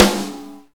drum-hitclap.mp3